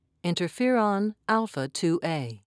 (in-ter-feer'on)